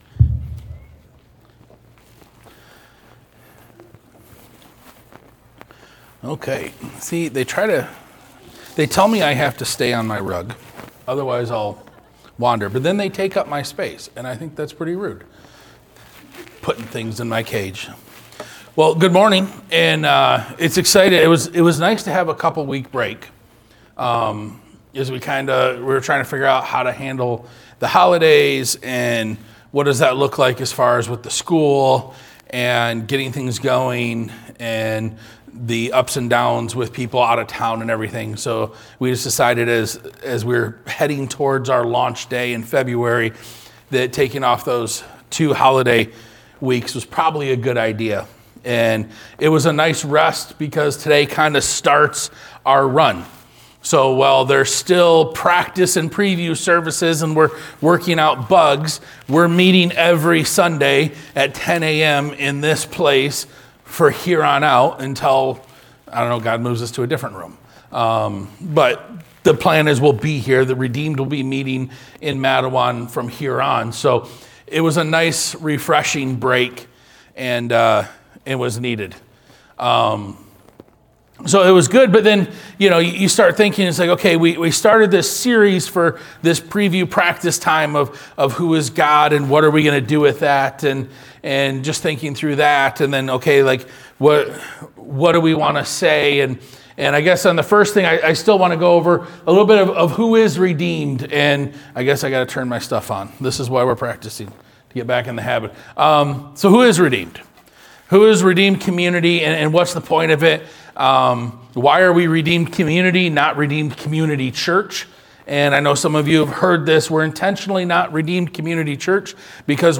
Sermon 1-11-26.mp3